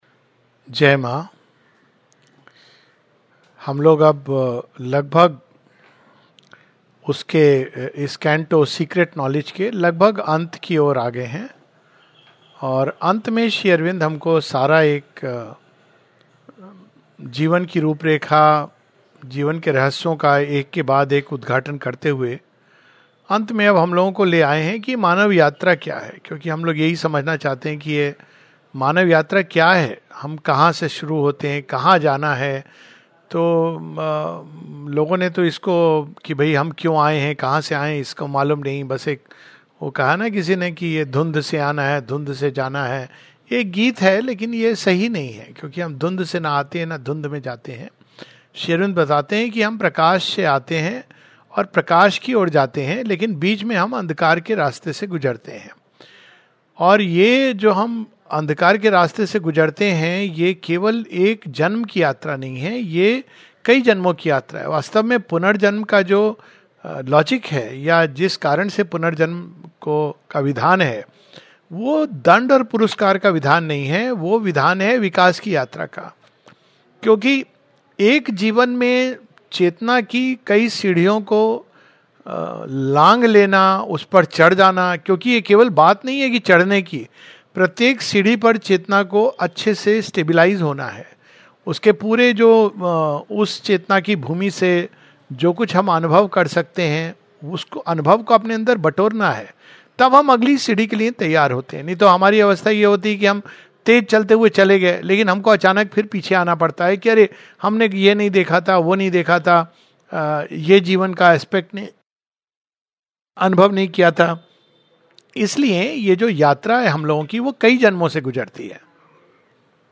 In today's class of the Savitri Yagya series we read lines from Book One Canto Four, The Secret Knowledge (pp. 69 - 71). A talk